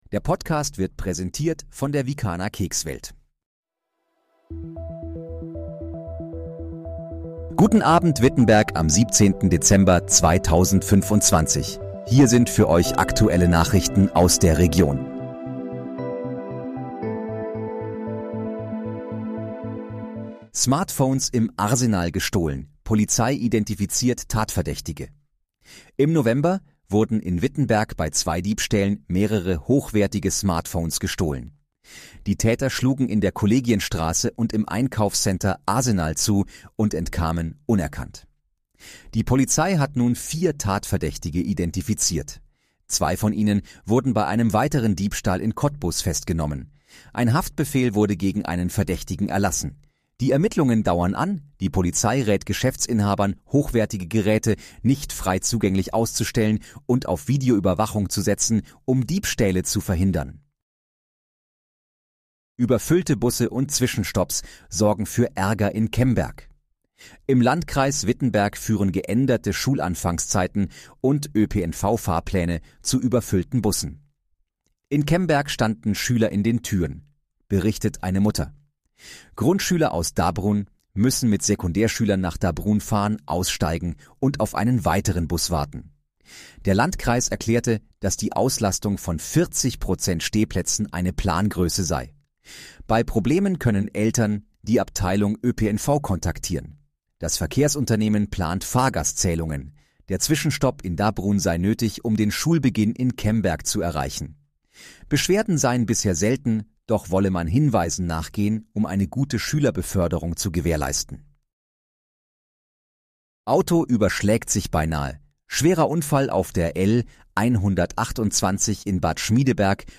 Guten Abend, Wittenberg: Aktuelle Nachrichten vom 17.12.2025, erstellt mit KI-Unterstützung
Nachrichten